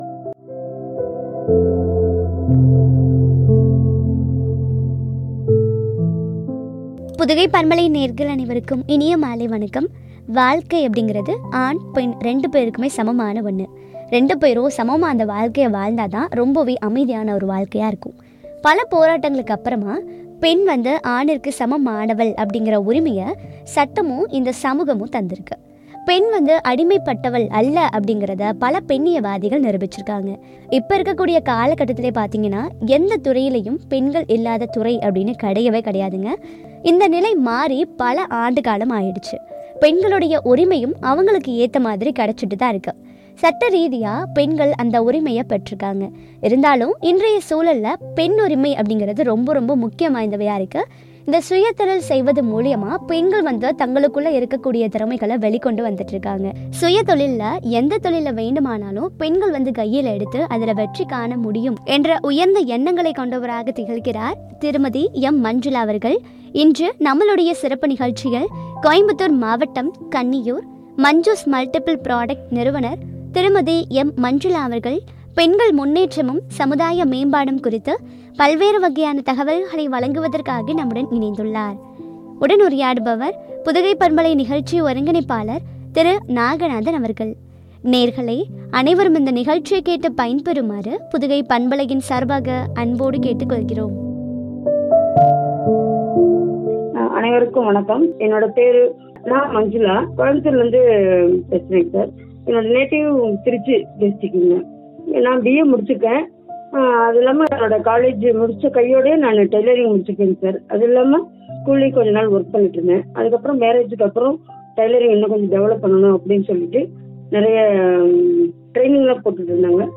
பெண்கள் முன்னேற்றமும்” குறித்து வழங்கிய உரையாடல்.